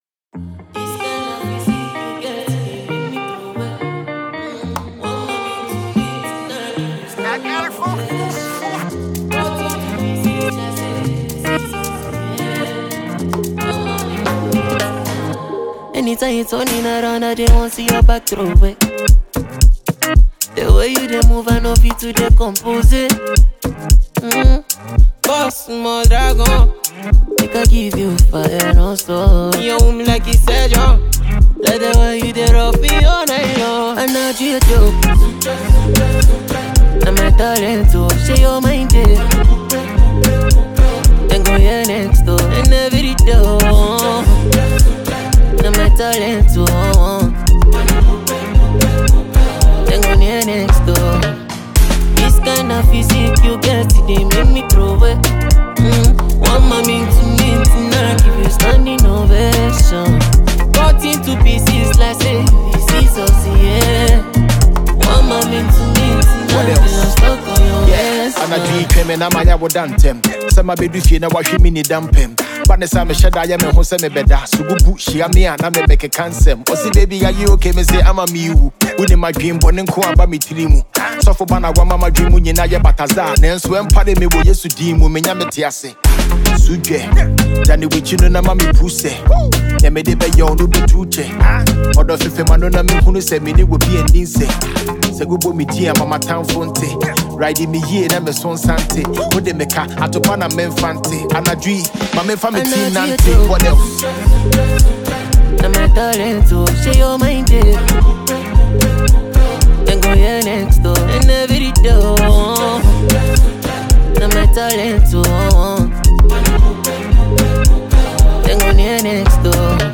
top notch rapper